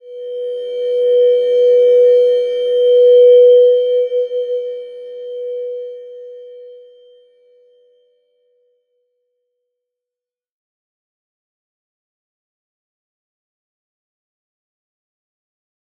Slow-Distant-Chime-B4-f.wav